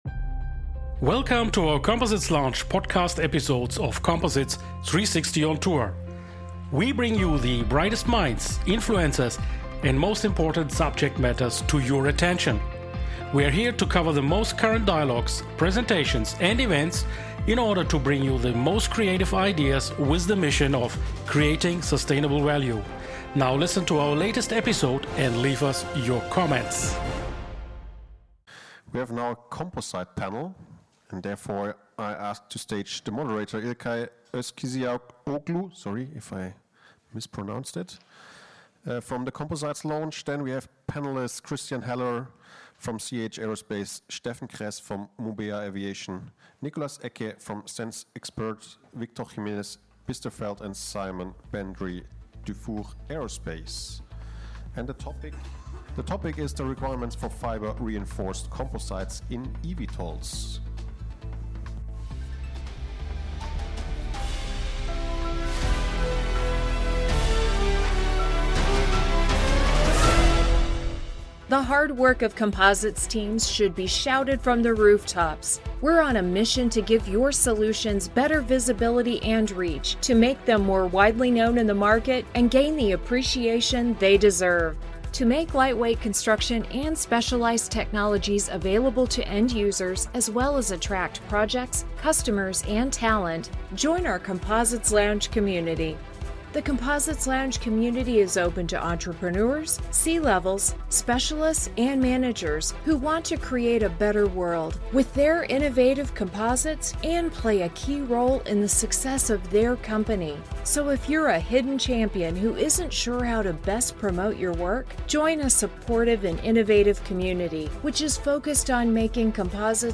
Beschreibung vor 1 Jahr Join our restream of our Composites Lounge Enduser Panel powered by JEC Group on The EVTOL Show in Stuttgart. This is the recorded and edited panel, which we turned into a special edition of an Composites Lounge Engineering Talk No 4: eVTOL Special Edition.